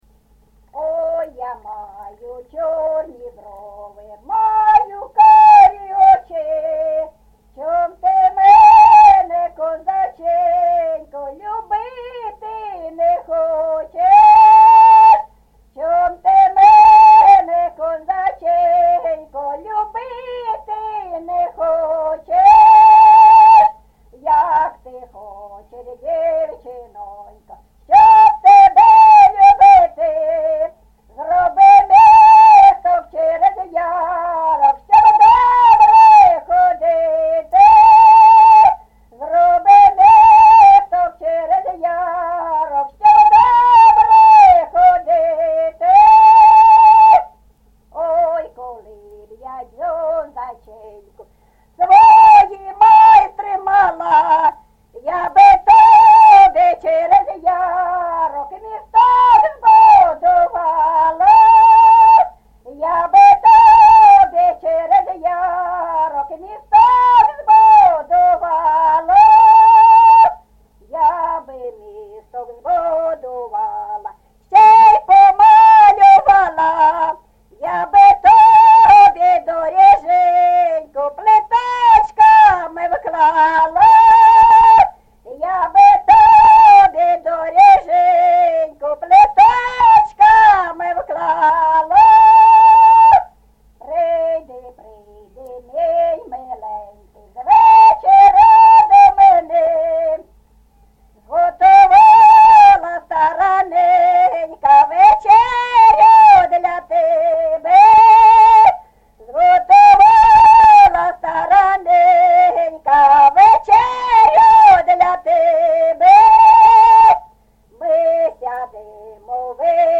ЖанрПісні з особистого та родинного життя
Місце записус. Свято-Покровське, Бахмутський район, Донецька обл., Україна, Слобожанщина